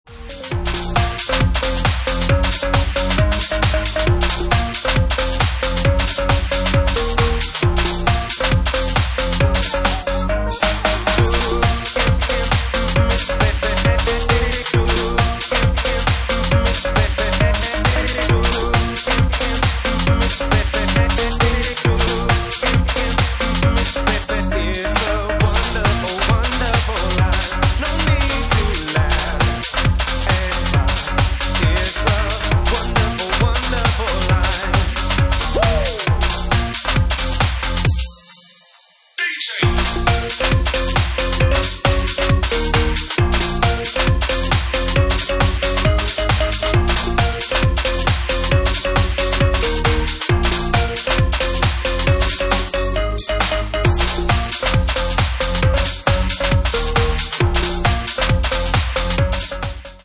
Genre:Bassline House
Bassline House at 68 bpm